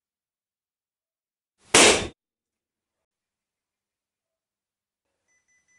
Tiếng Bóng Bay, bóng hơi Nổ to
Tiếng Bóng Bay, bóng hơi Nổ bốp… nhẹ hơn Tiếng Điện Nổ (âm thanh thực)
Thể loại: Tiếng đồ vật
Description: Một Balloon POP sound effect chân thực, mô phỏng tiếng nổ lớn khi bóng bay bị kim chọc.
tieng-bong-bay-bong-hoi-no-to-www_tiengdong_com.mp3